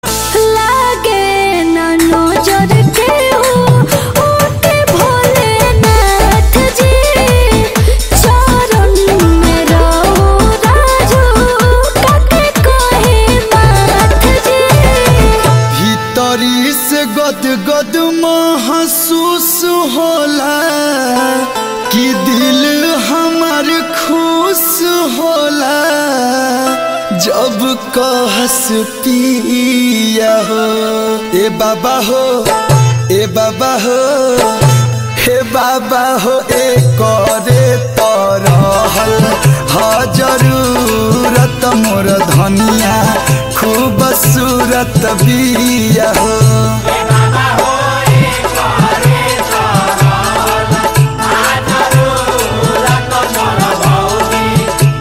Bhojpuri Bolbum Ringtones